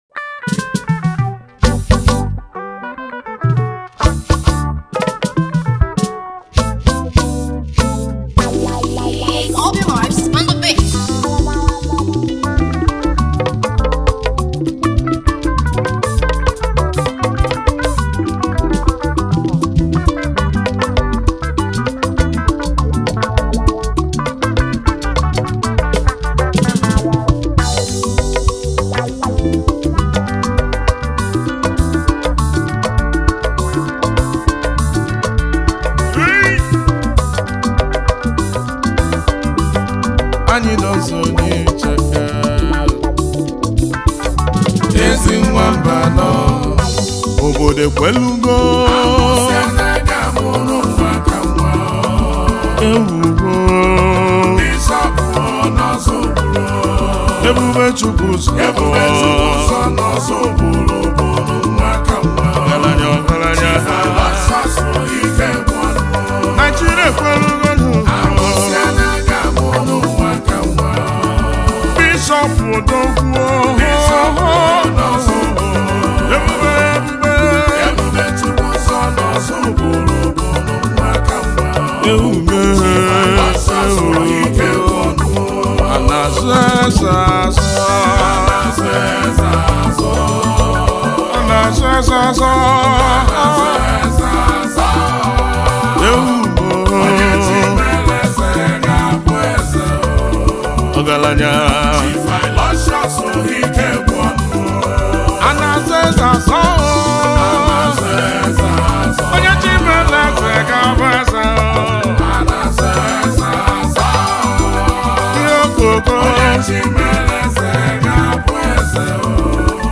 Igbo Music and Highlife